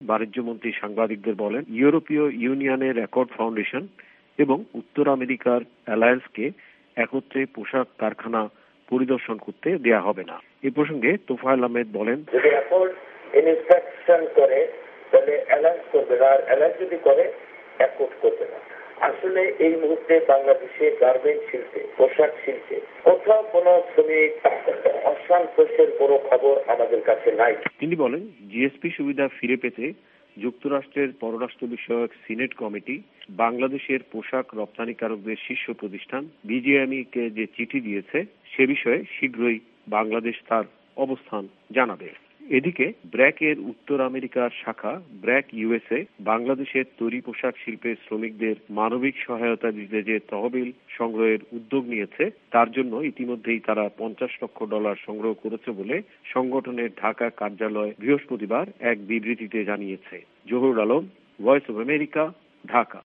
বিস্তারিত প্রতিবেদন :